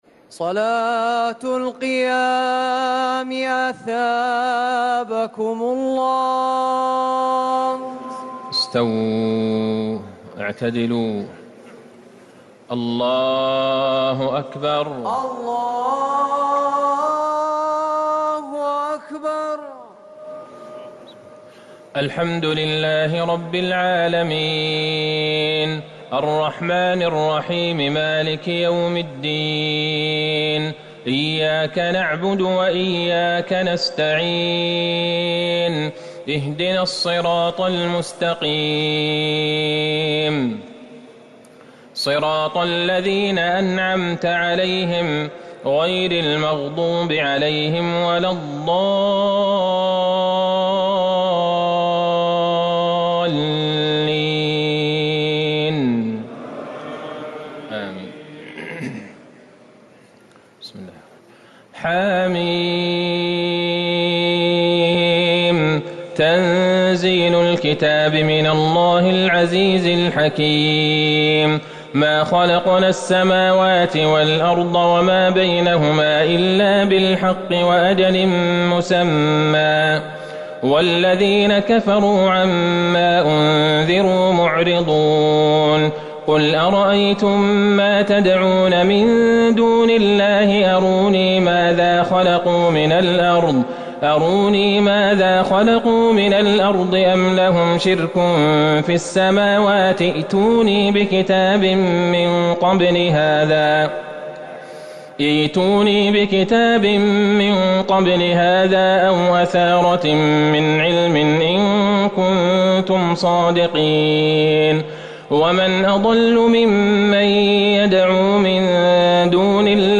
تراويح ٢٥ رمضان ١٤٤٠ سورة الأحقاف ومحمد > تراويح الحرم النبوي عام 1440 🕌 > التراويح - تلاوات الحرمين